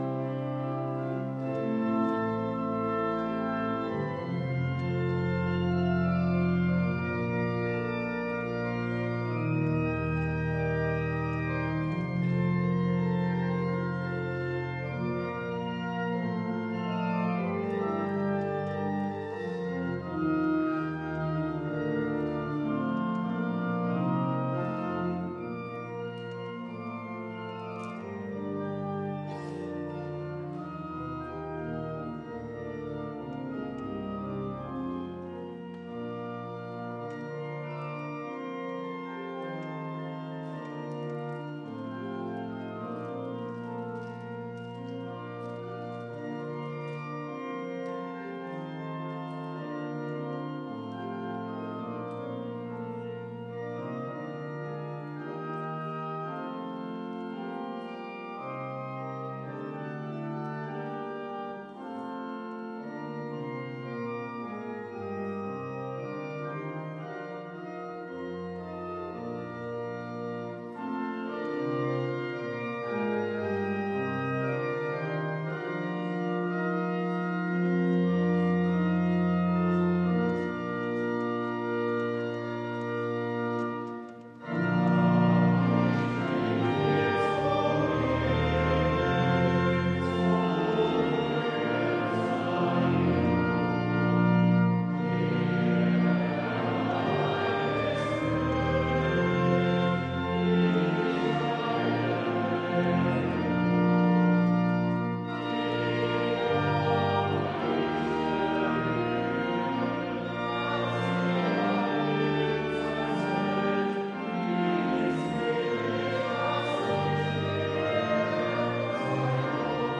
Du, meine Seele, singe... (LG 373,10) Ev.-Luth. St. Johannesgemeinde Zwickau-Planitz
Audiomitschnitt unseres Gottesdienstes am 11.Sonntag nach Trinitatis 2024